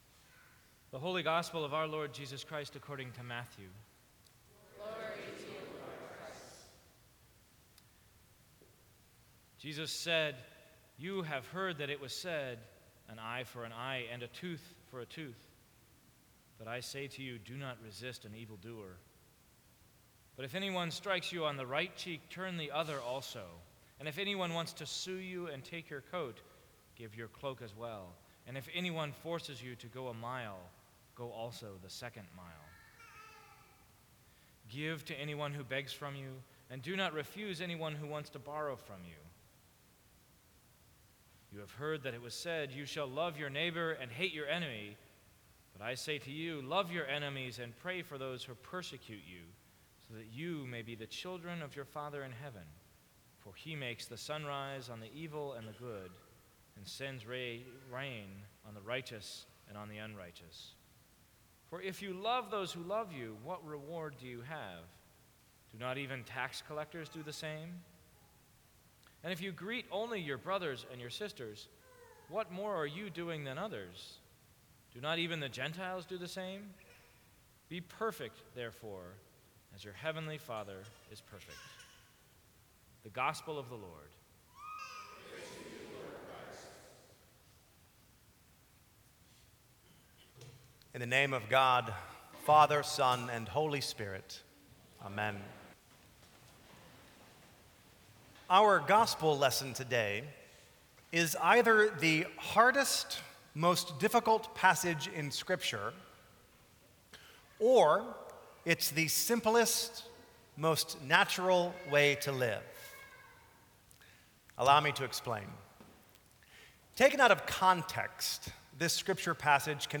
Sermons from St. Cross Episcopal Church February 23, 2014.